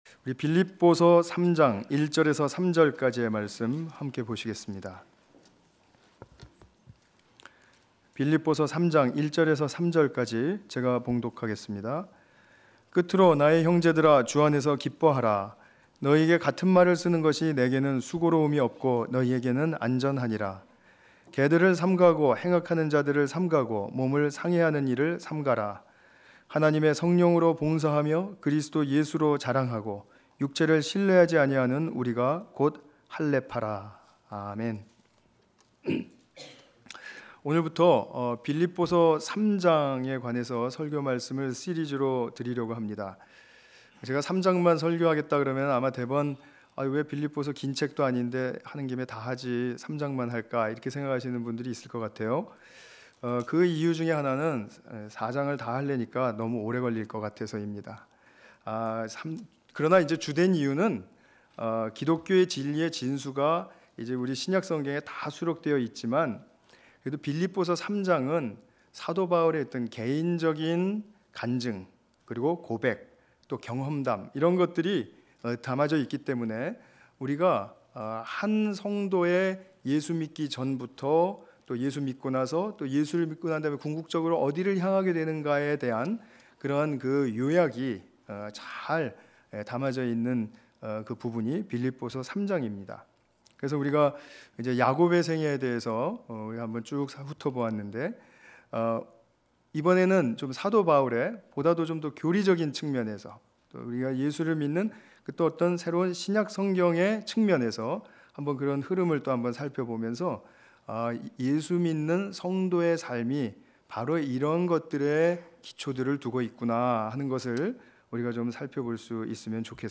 빌립보서 3장 1 ~ 3절 관련 Tagged with 주일예배